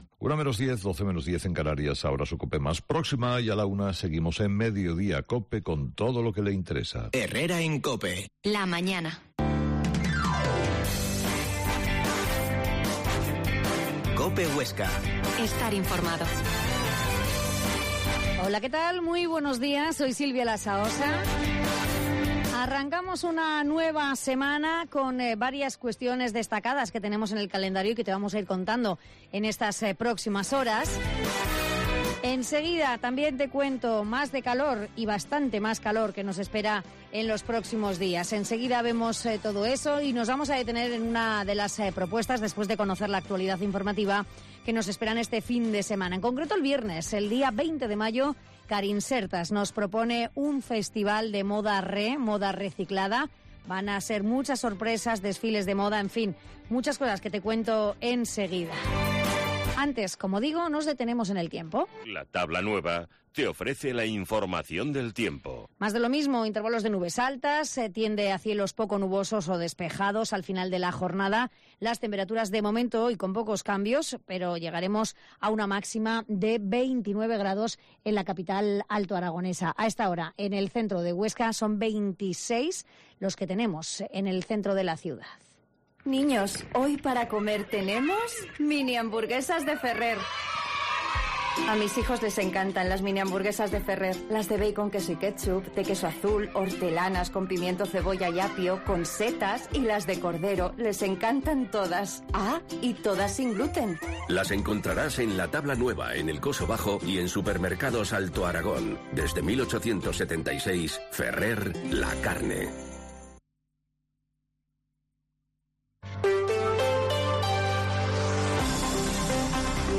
La Mañana en COPE Huesca - Informativo local Herrera en Cope Huesca 12,50h.